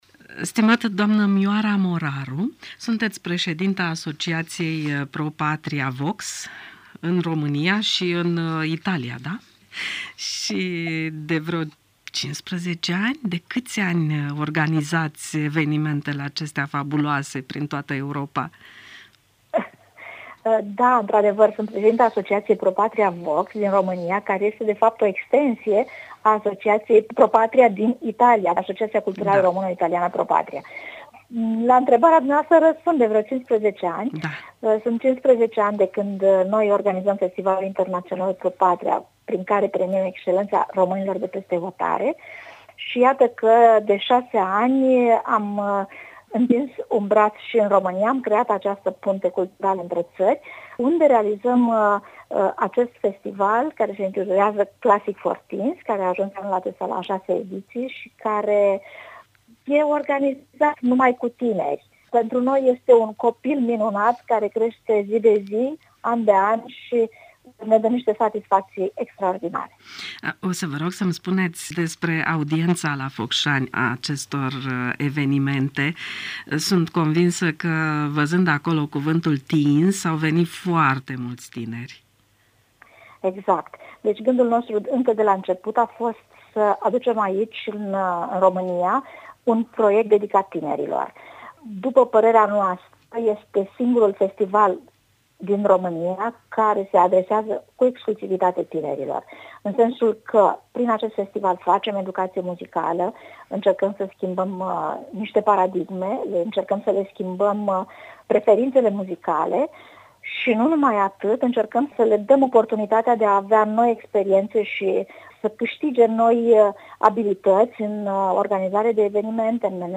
Interviu